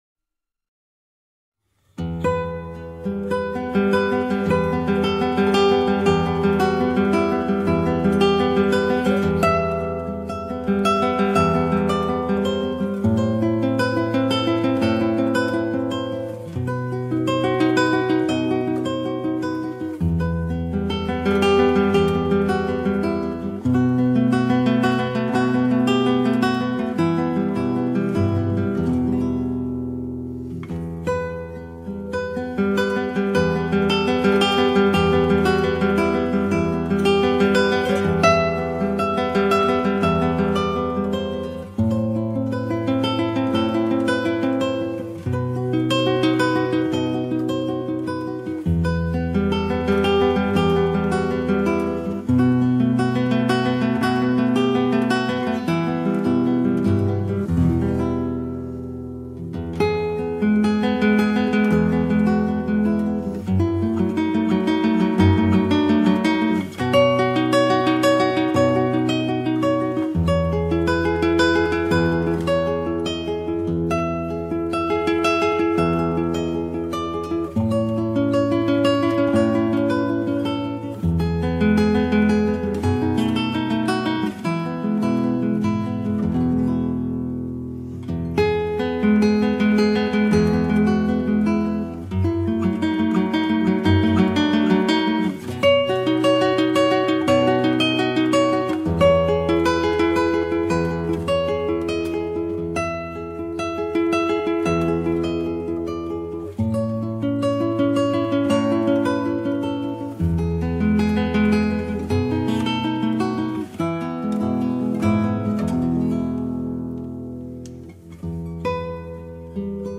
virtuosos acordes a guitarra